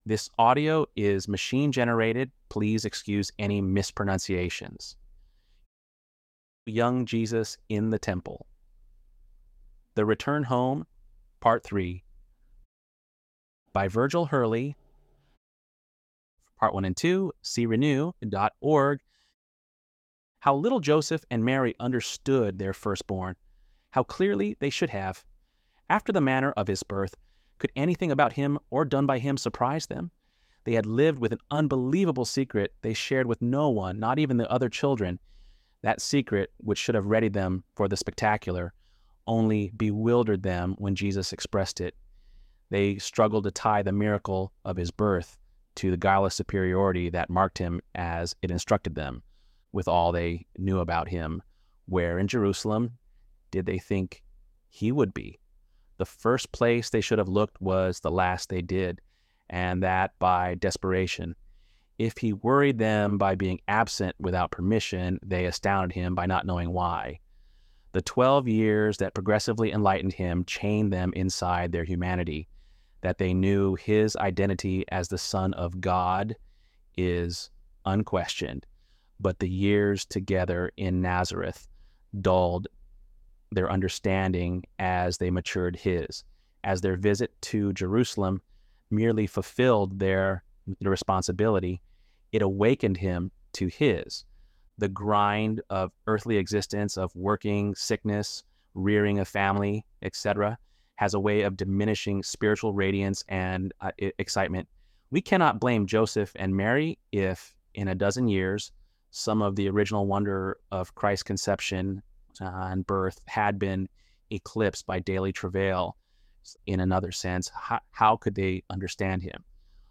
ElevenLabs_11.mp3